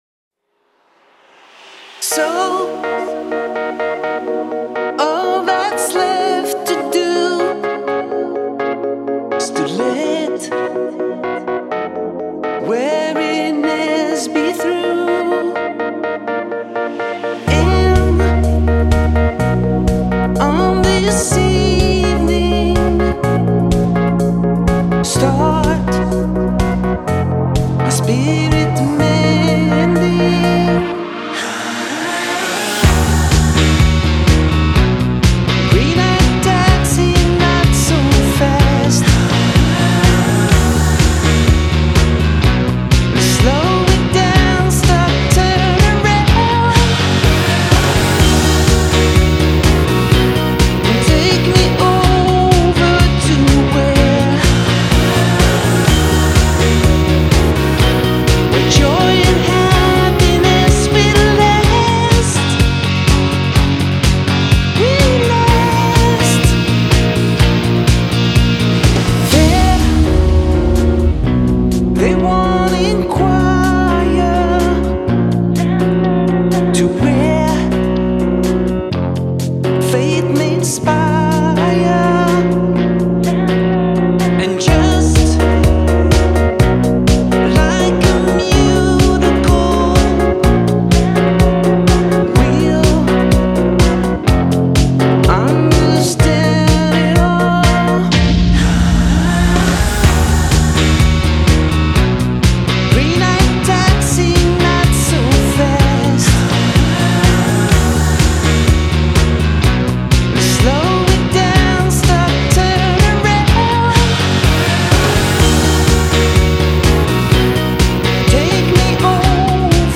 грандиозный концерт в лужниках
ремикс